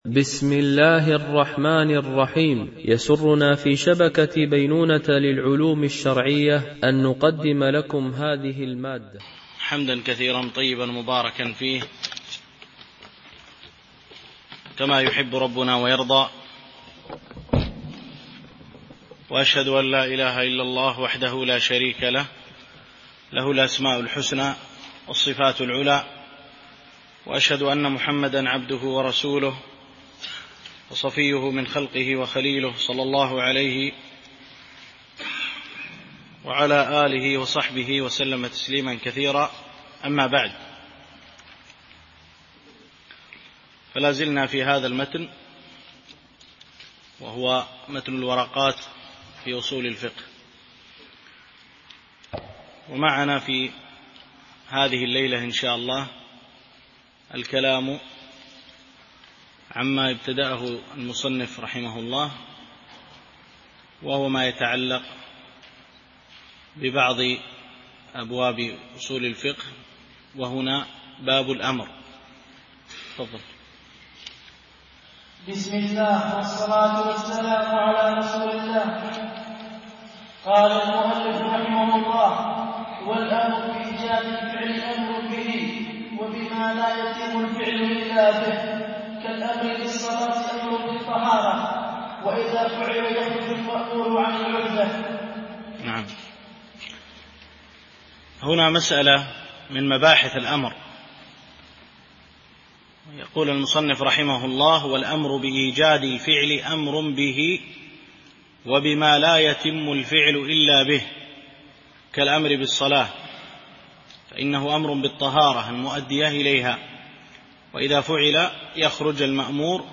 شرح الورقات في أصول الفقه للجويني ـ الدرس 5